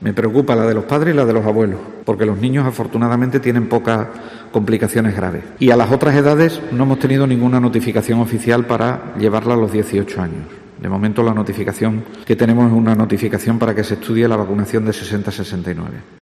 El consejero extremeño ha realizado estas declaraciones este viernes en una rueda de prensa en Mérida a preguntas de los medios sobre la ampliación de la tercera dosis de la vacuna a diferentes grupos de edades.